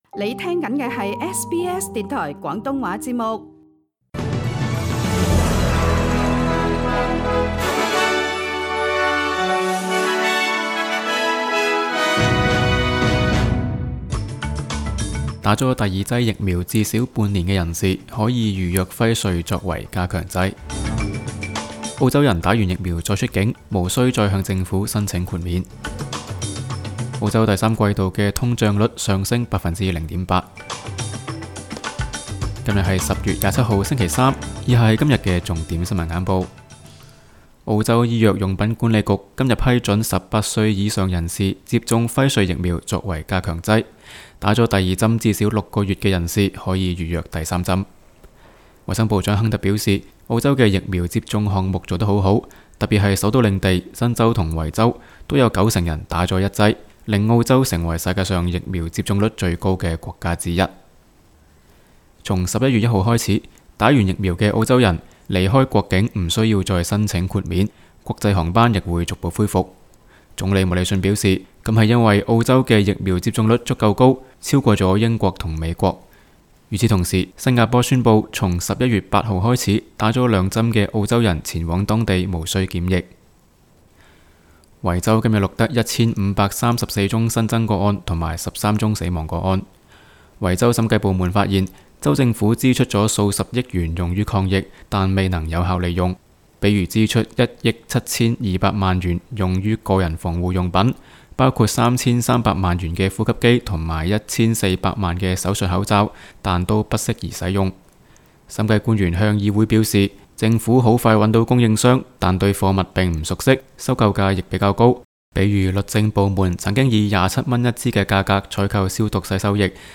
SBS 新聞簡報（10月27日）